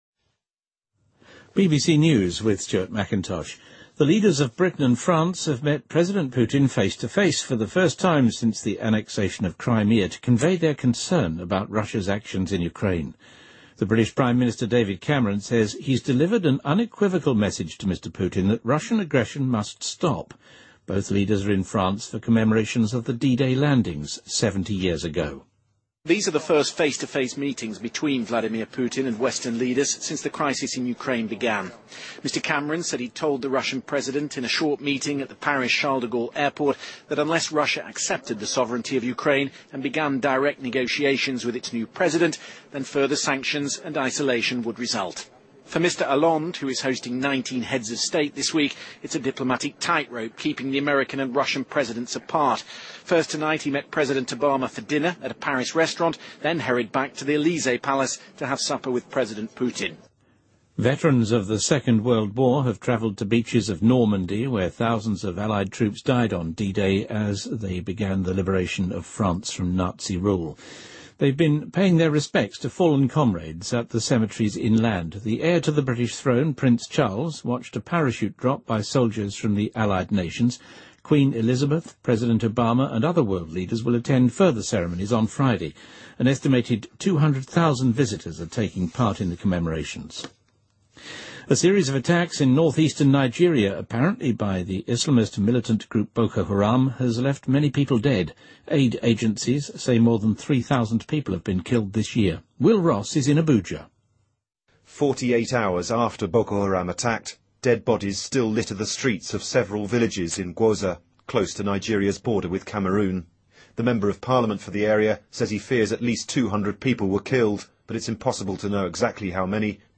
BBC news:自乌克兰危机以来普京首次与西方多名政要在法国会晤|BBC在线收听